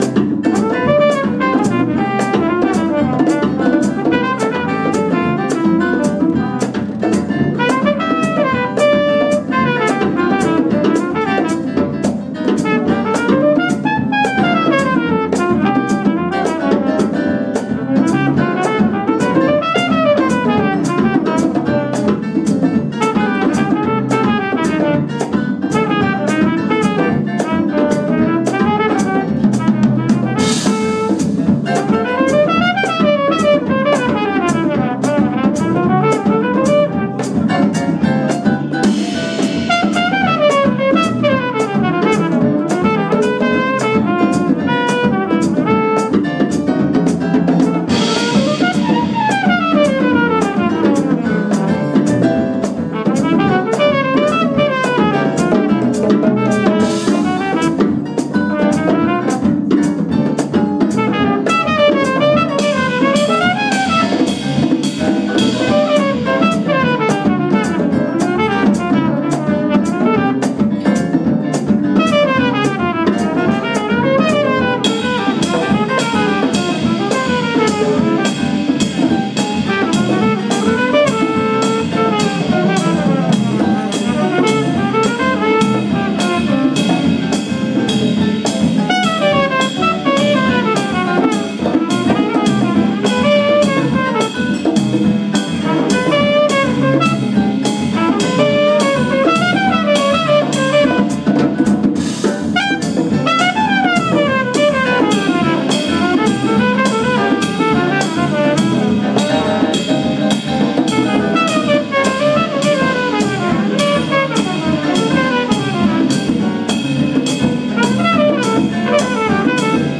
live at Steamers in Fullerton, CA